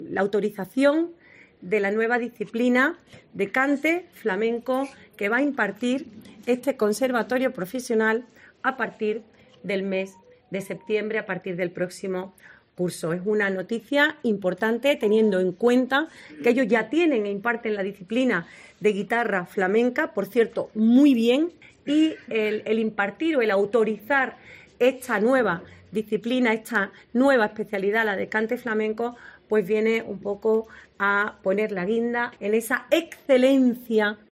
Patricia del Pozo, Consejera de Educación